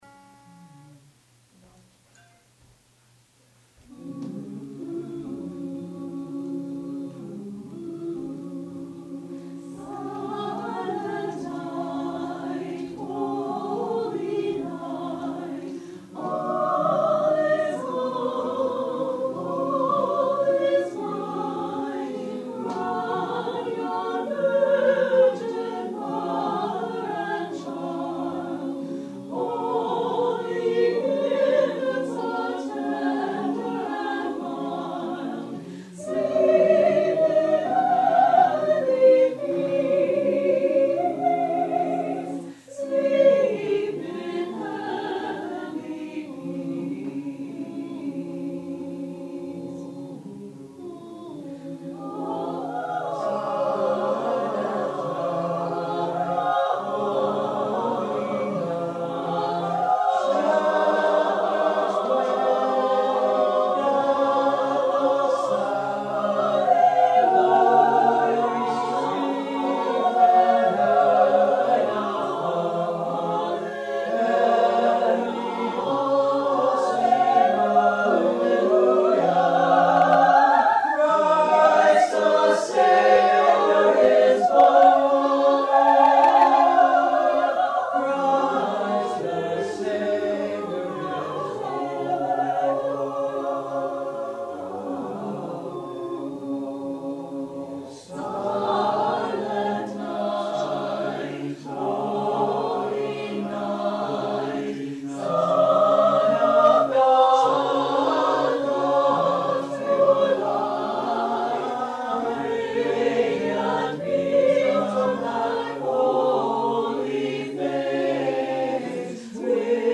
November 23, 2008 Rehearsal: